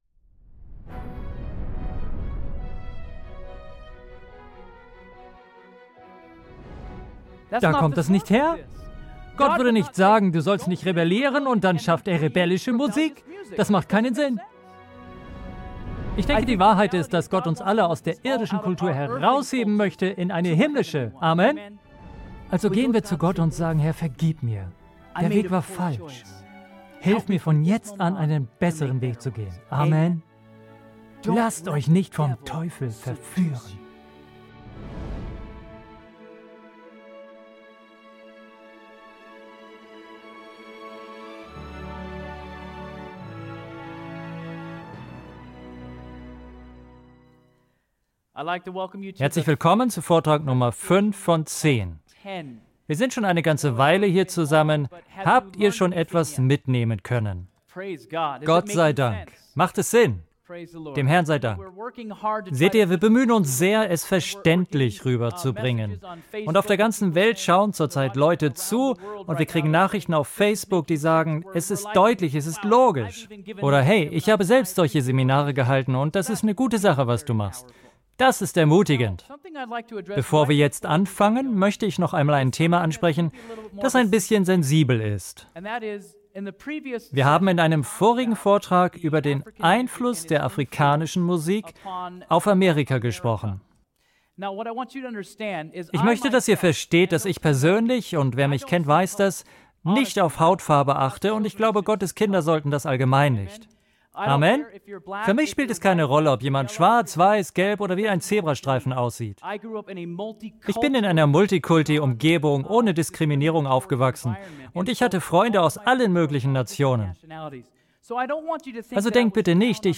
Dann bist du bei diesem Seminar genau richtig.